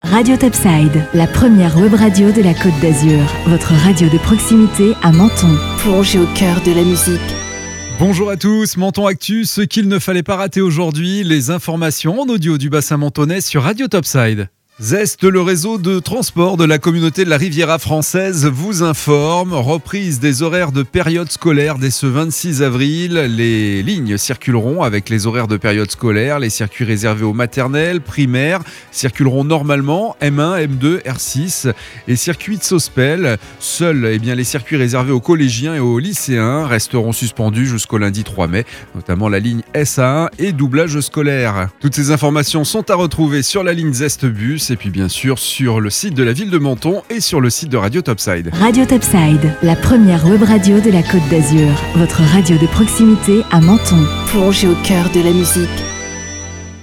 Menton Actu - Le flash info du lundi 26 avril 2021